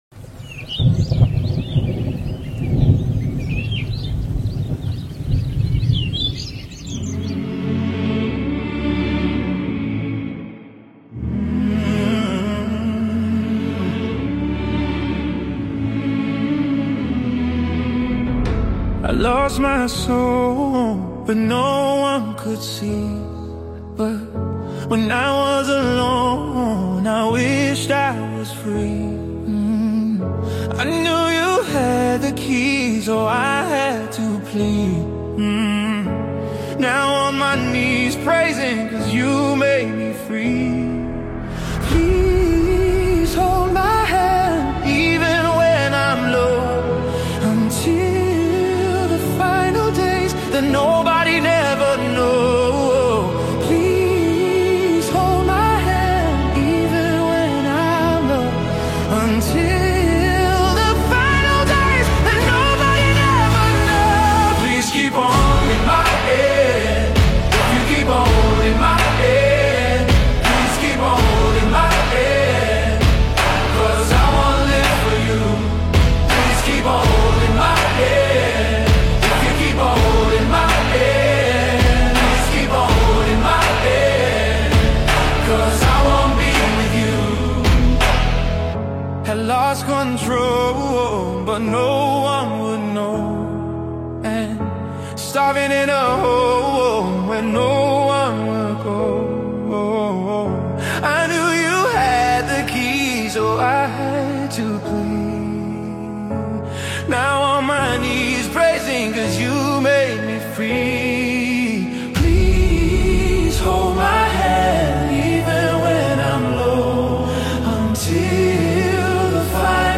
Emotionally, the song leaves a calming and hopeful feeling.